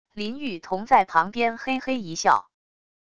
林喻曈在旁边嘿嘿一笑wav音频生成系统WAV Audio Player